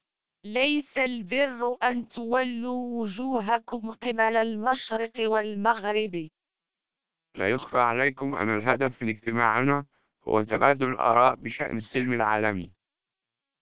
Experts haven't found significant difference in sounding between SPR 1200 and MELPe 1200 vocoders.
You can play and listen short samples of the source speech as well as the speech processed by these vocoders for any of 20 languages, using links in the table below.